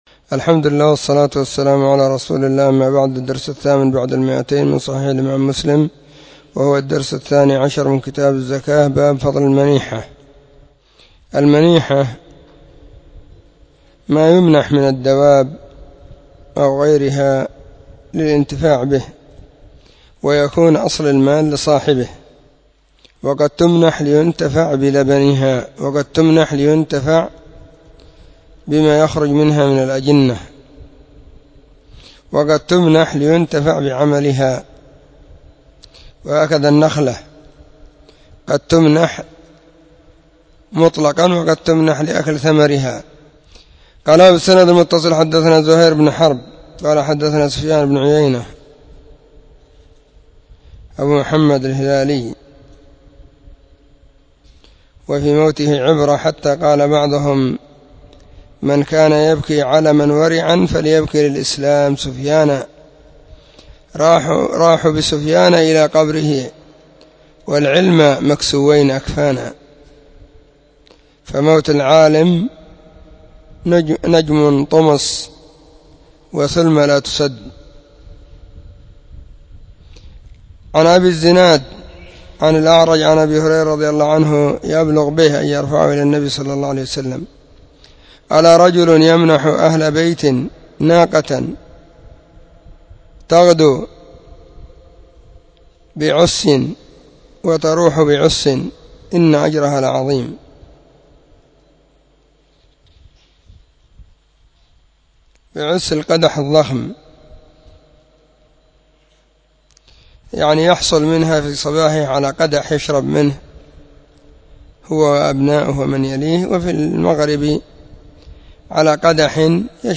📢 مسجد الصحابة – بالغيضة – المهرة، اليمن حرسها الله.
كتاب-الزكاة-الدرس-12.mp3